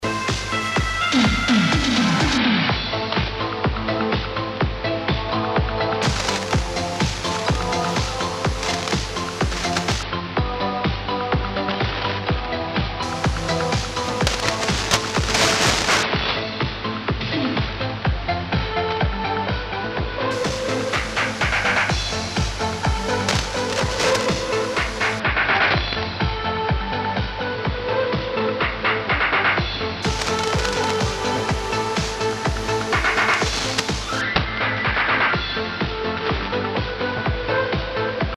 Демонстрация работы фильтра
Звучит Мастер АМ. Запись с линейного выхода, как в схеме на первой странице, диапазон "тройка" вчера вечером..